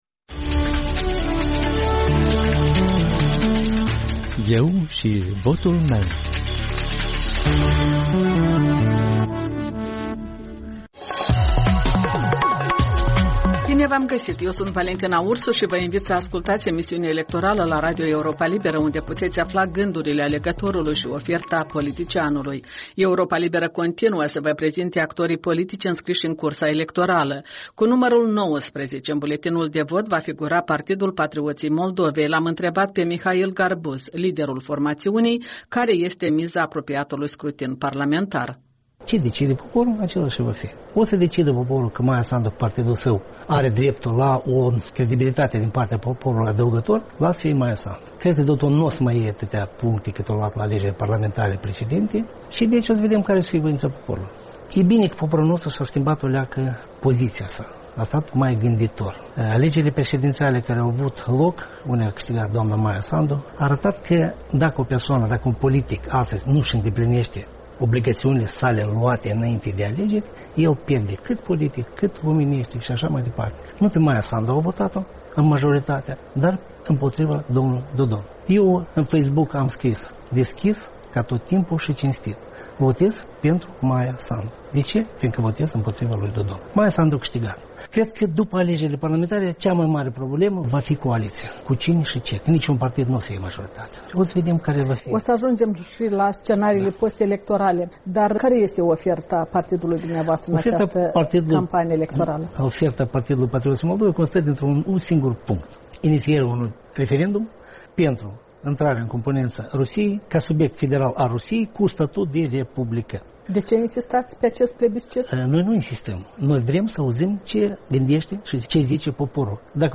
Europa Liberă lansează tradiționalul podcast electoral